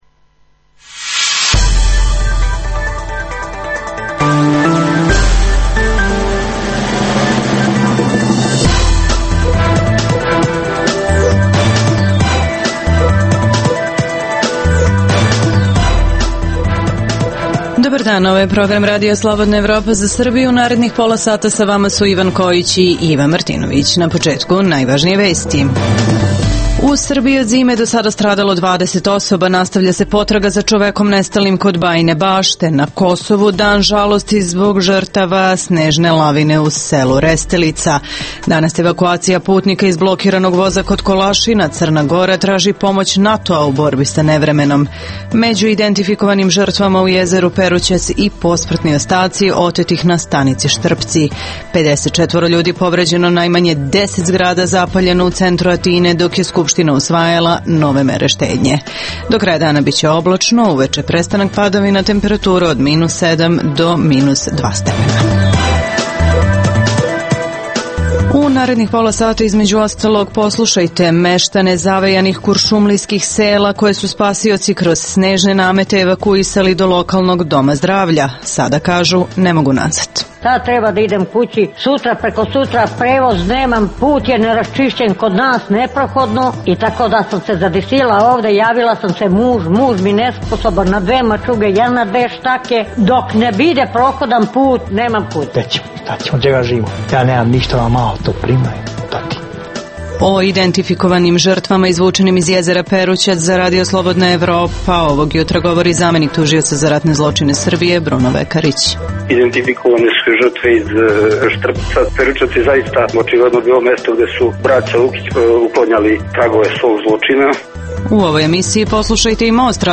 U emisiji poslušajte: - Čućete meštane zavejanih sela kod Kuršumlije koje su spasioci kroz snežne namete evakuisali do lokalnog Doma zdravlja.
- O identifikovanim žrtvama, koje su pronađene u jezeru Perućac za RSE govori zamenik tužioca za ratne zločine Srbije Bruno Vekarić. - Poslušajte i Most RSE u kojem se razgovaralo o tome da li se Srbija vraća u devedeste.